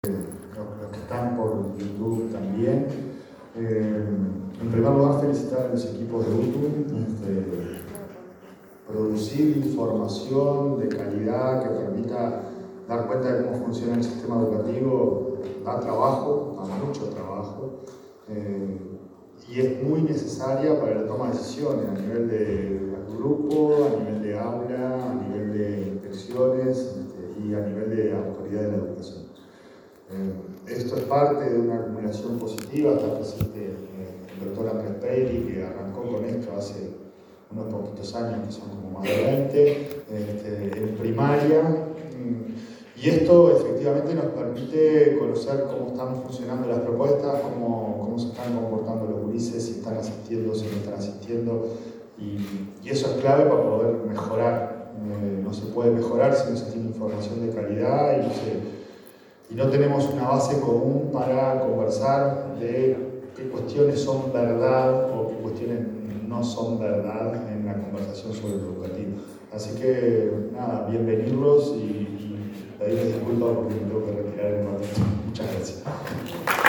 El presidente de la Administración Nacional de Educación Pública (ANEP), Pablo Caggiani, hizo uso de la palabra en la presentación del Monitor